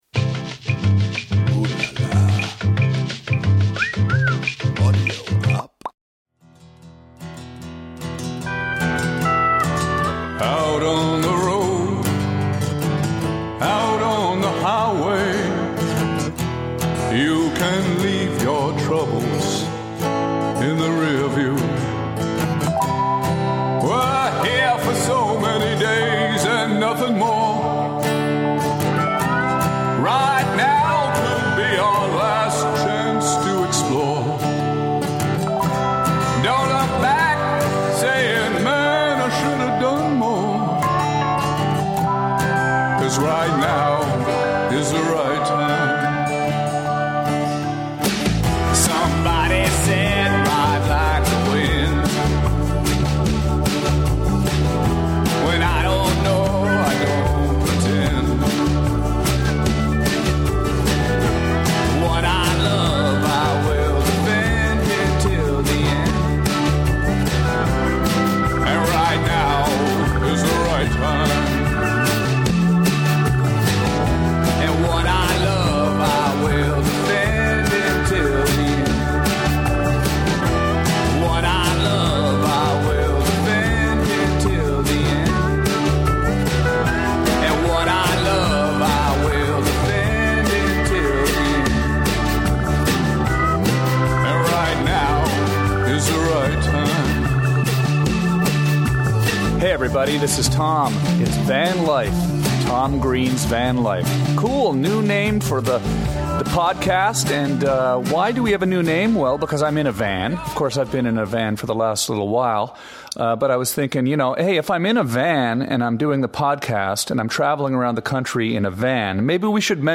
Tom Green broadcasting from Cabeza Prieta Wilderness area details some of his adventures this week from the border of Mexico and Arizona. As military aircraft scream overhead on their way to bombing runs in the adjacent Barry F Goldwater test range, Tom talks about his life on the road and also has a call from punk rock legend John Katsner. Known as the front man from legendary punk rock bands like the Doughboys, the Asexuals, and All Systems Go!, John tells some of his amazing stories from van life as a touring rock and roll musician.